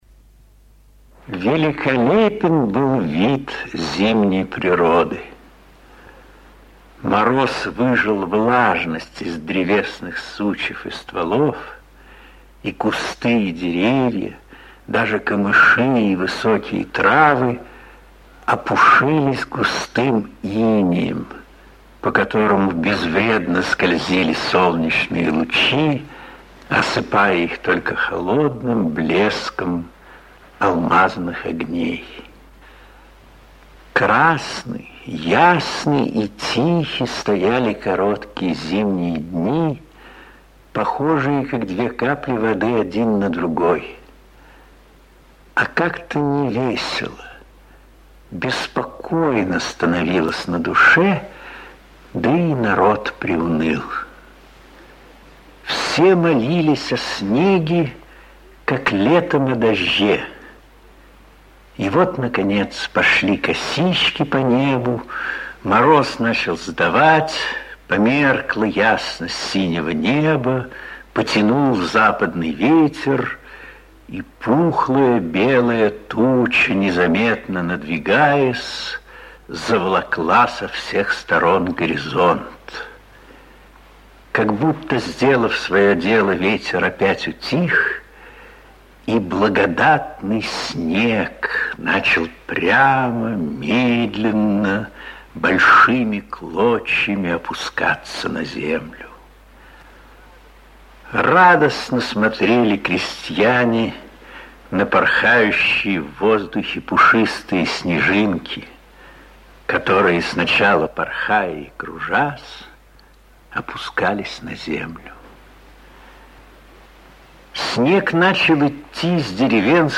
Он записал на радио своё исполнение этого рассказа.
Голос Велихова – особенный, ни на кого не похожий, бархатистый, выразительный, как бы переливающийся разными красками. Каждую буковку, каждый звук можно словно увидеть, прочувствовать, и в то же время это абсолютно цельное, яркое произведение.